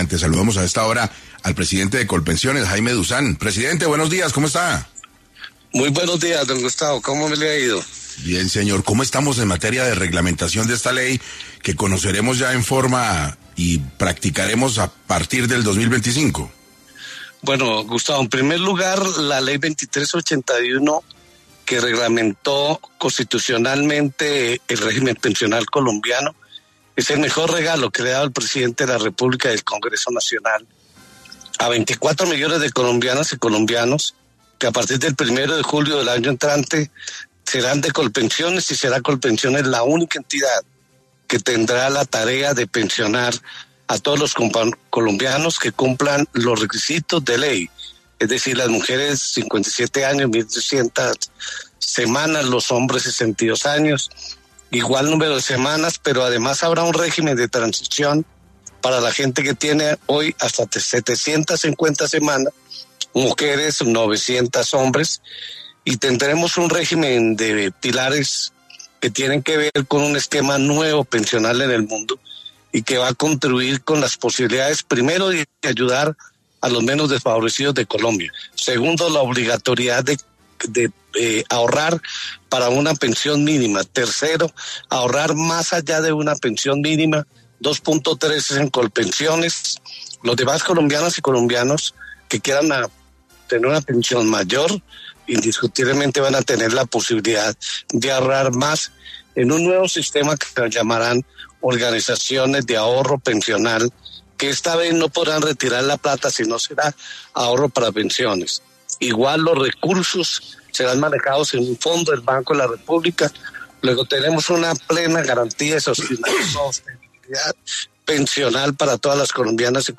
En 6AM de Caracol Radio estuvo Jaime Dussán, presidente de Colpensiones, quien habló sobre cuál será la transformación estructural que tendrá el sistema pensional colombiano